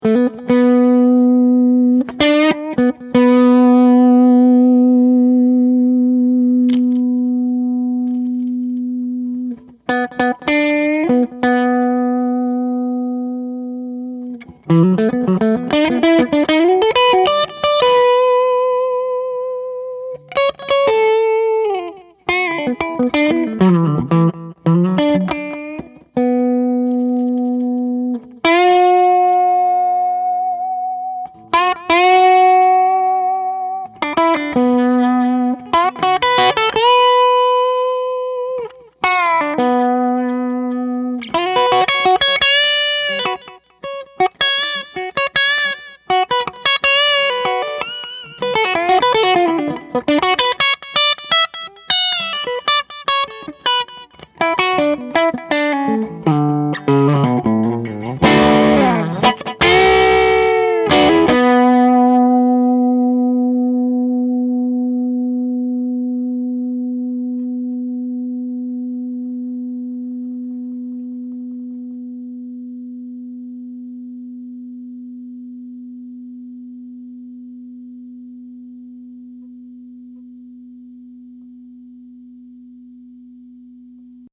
We used a Two Rock 112, Open back cabinet with a Tone Tubby Alnico 16:
Gibson 335